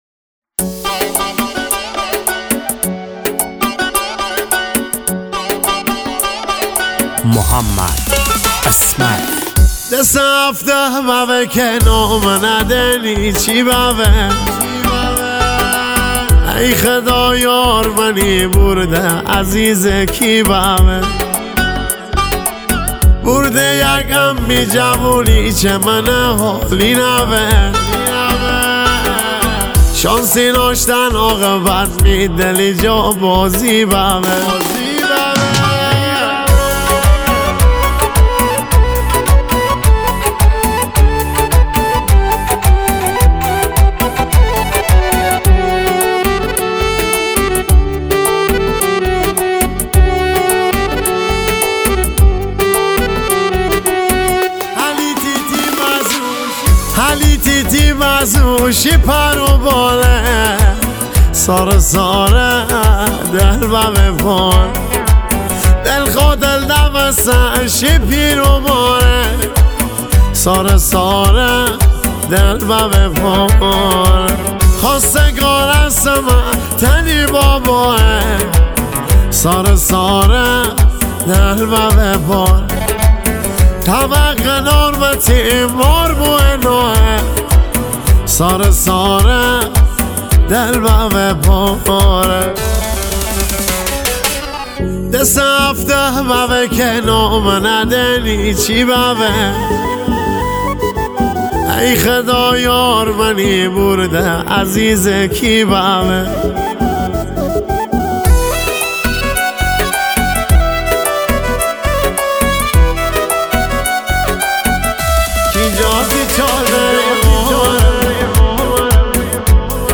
ریتمیک ( تکدست )
با سبک شاد مازندرانی